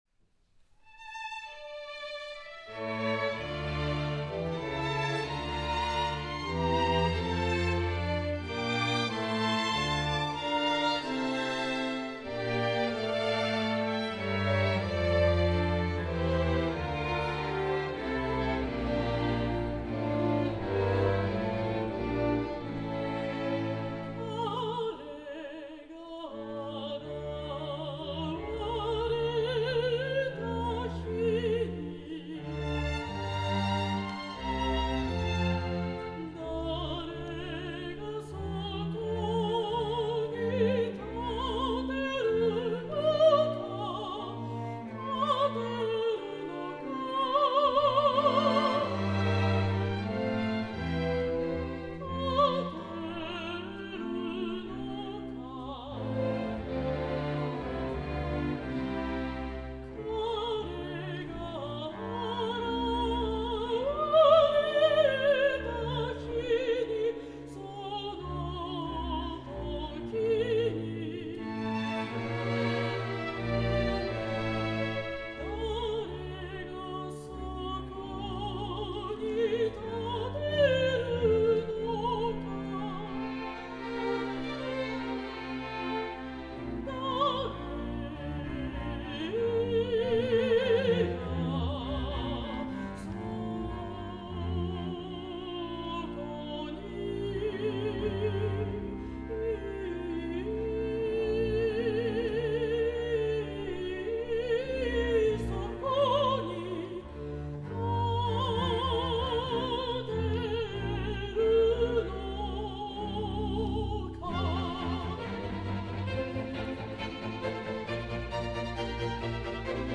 Kashiwa Concert Hall